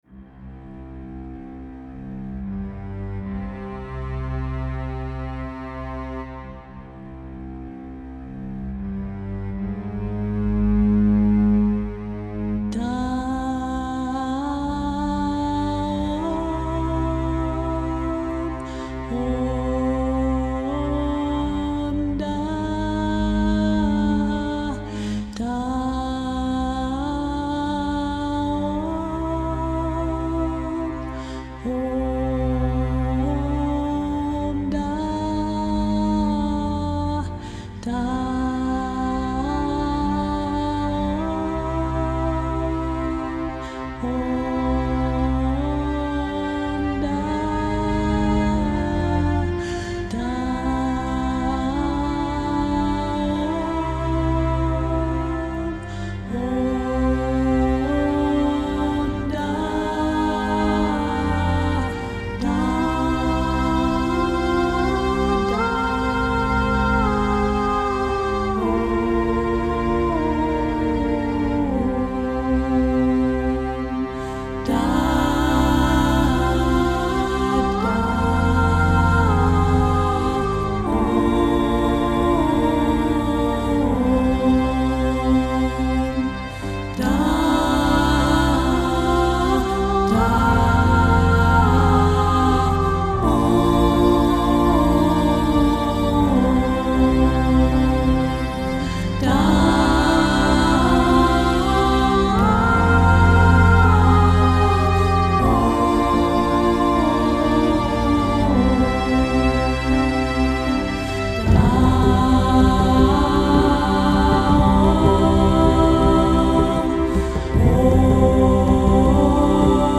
devotional chants
• Tags: music